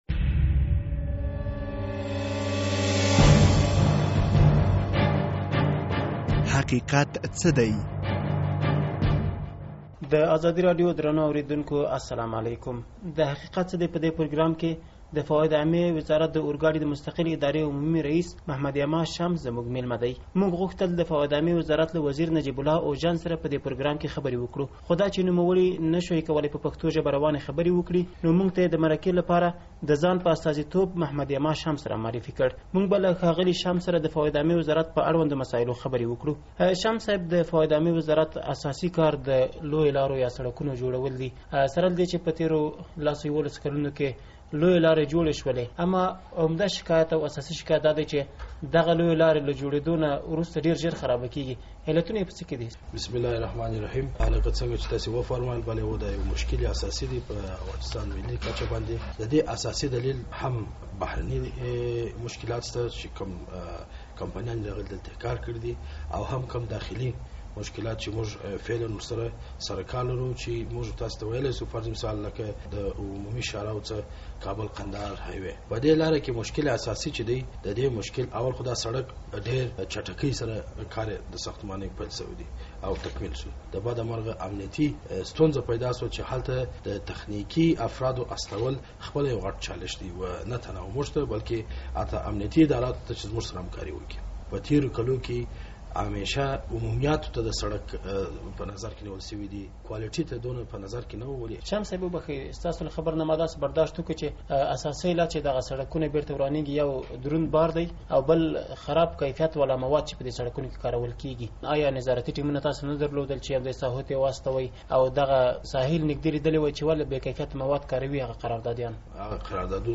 د حقیقت څه دی، په دې پروګرام کې د فواید عامې وزارت د اورګاډي د مستقلې ادارې له عمومي ریس محمد یما شمس سره غږیدلي یو.